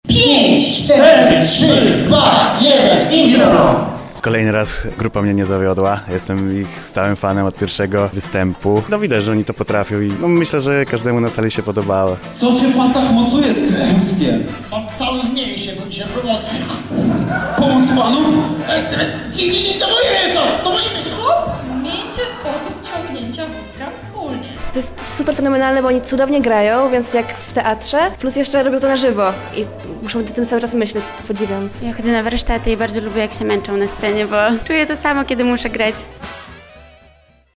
Pomaganie przed rozśmieszanie. Improwertycy wystąpili w Centrum Kongresowym UP
Grupa improwizatorów zagrała scenki zgodnie z sugestiami publiczności.